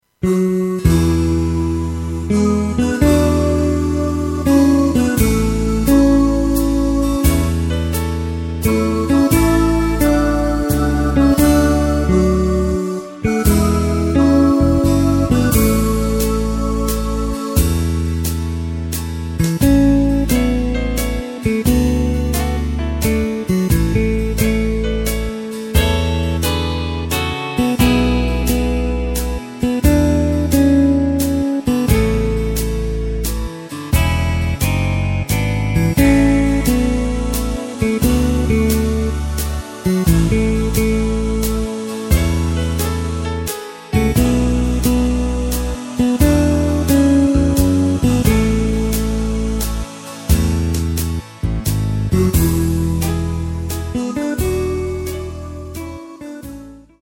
Takt:          3/4
Tempo:         96.00
Tonart:            C
Schlager aus dem Jahr 1972!